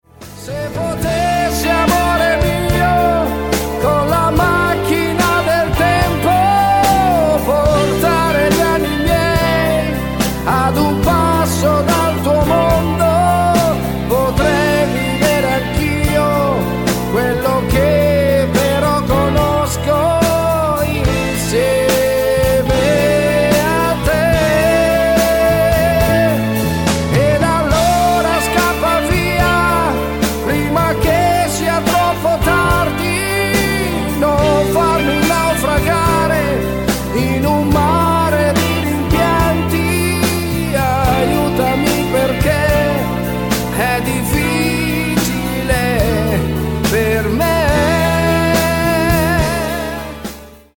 TERZINATO  (4.10)